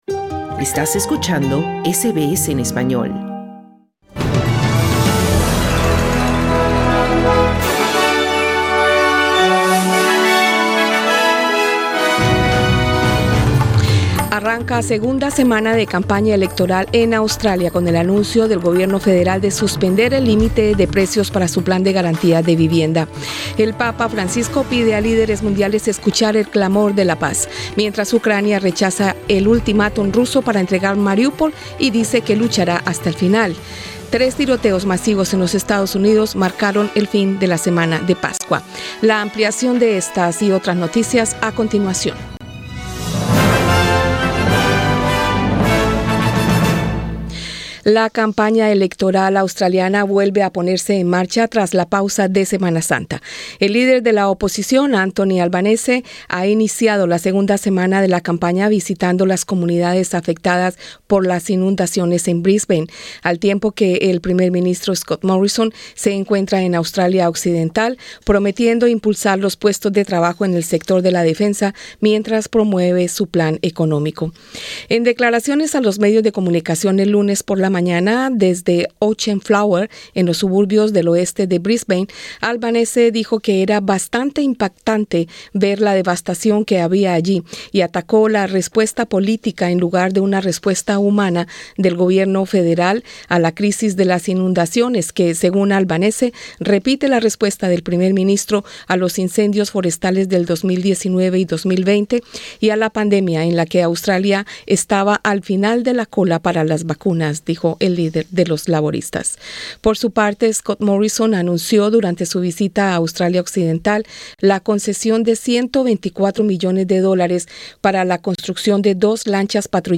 Noticias SBS Spanish | 18 abril 2022